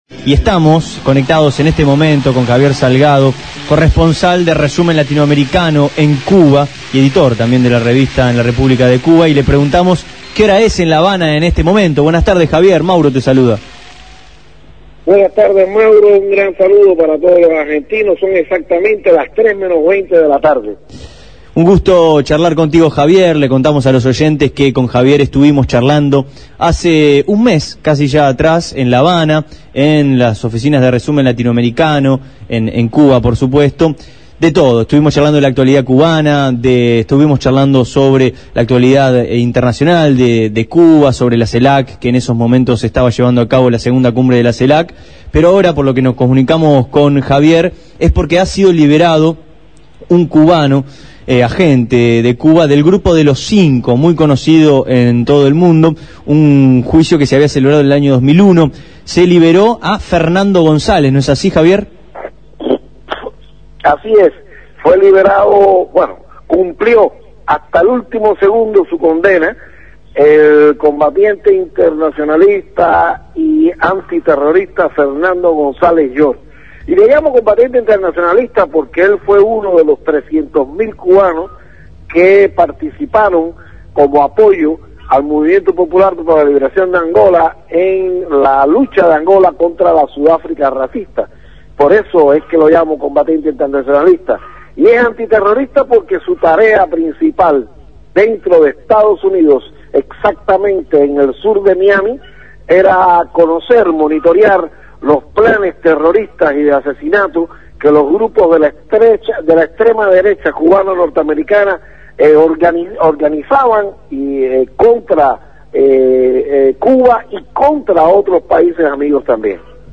fue entrevistado en Abramos la Boca. Hizo un informe detallado sobre la liberación en Estados Unidos, luego de cumplir su condena, de Fernando González combatiente internacionalista y antiterrorista.